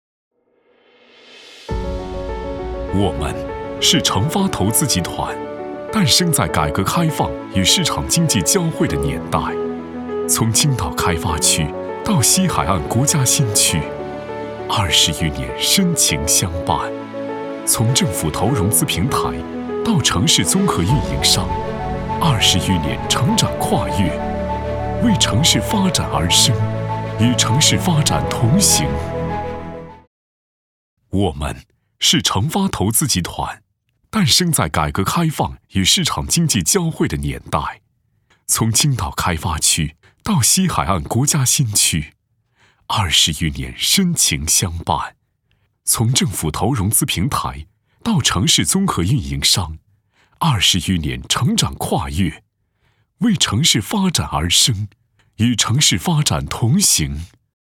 A男44号
【专题】大气偏轻快 城发宣传片.mp3